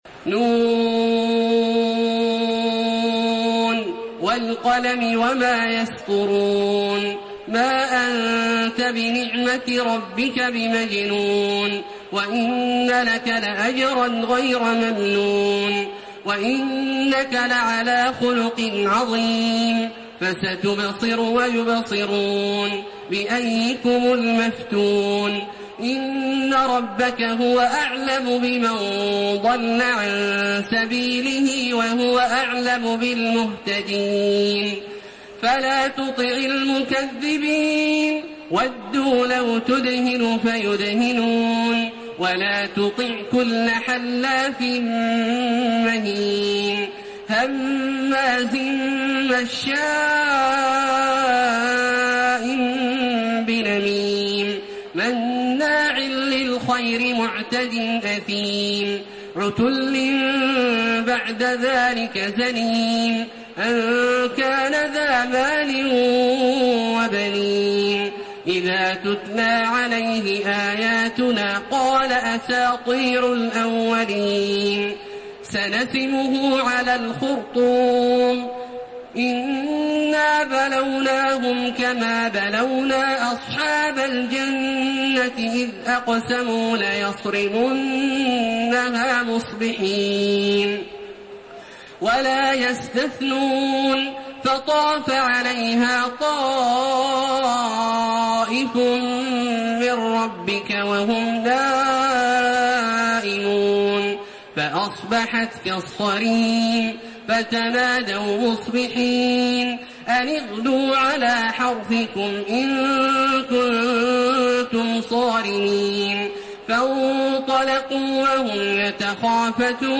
تحميل سورة القلم بصوت تراويح الحرم المكي 1432
مرتل